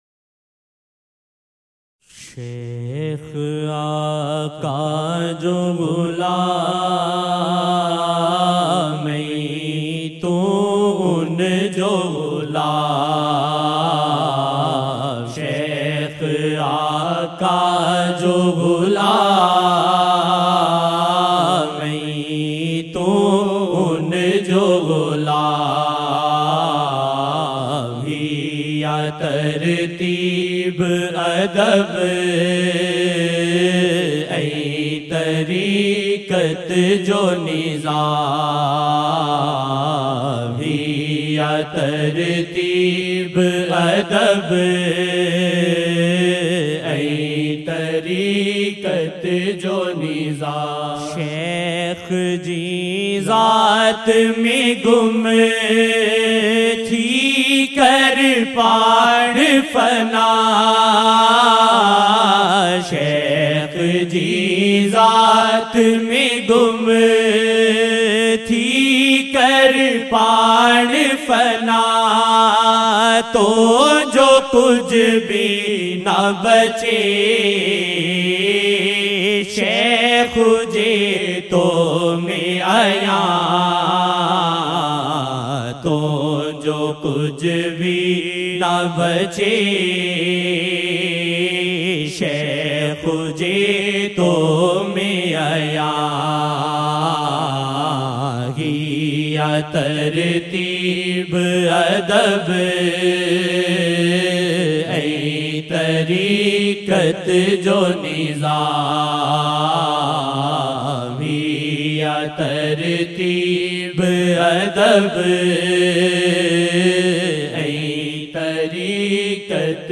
SHEIKH AAQA SAW jo Ghulaam ain tu un jo Ghulaam He aa Tarteeb-e-Adab ain Tareeqat Jo Nizaam 03 Aug 2017 New Naat Shareef